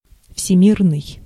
Ääntäminen
IPA: /y.ni.vɛʁ.sɛl/